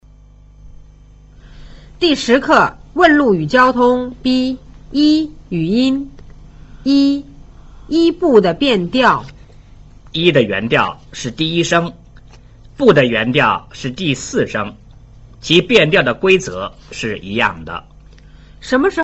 1. “一”, “不” 的變調
“一” 的原調是第一聲, “不”的原調是第四聲。